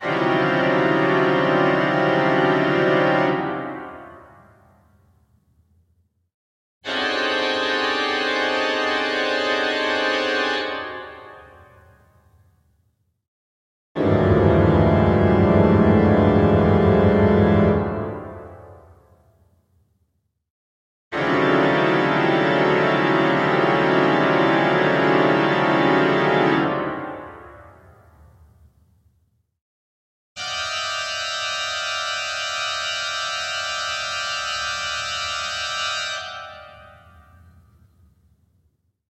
Жуткие звуки органа